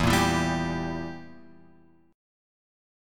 Edim chord {0 1 2 0 x 3} chord
E-Diminished-E-0,1,2,0,x,3.m4a